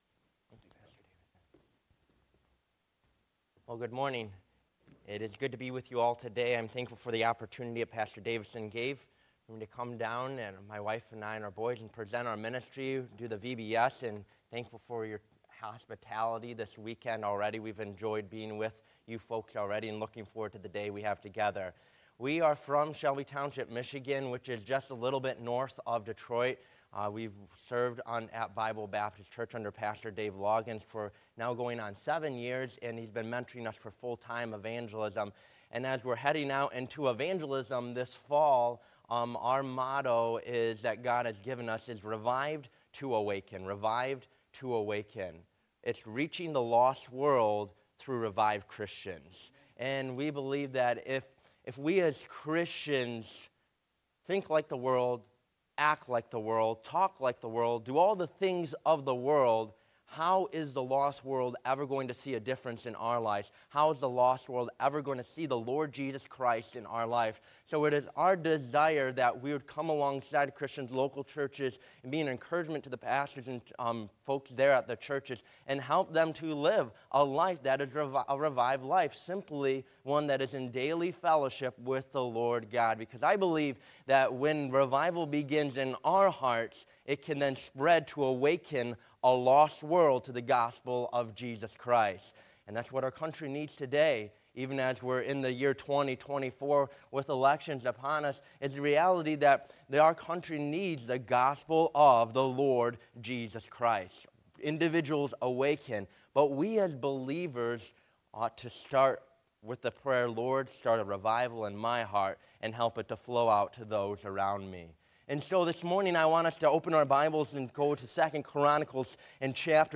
Passage: 2 Chron. 18 Service Type: Adult Sunday School Class « Be faithful even when you don’t understand what God is doing.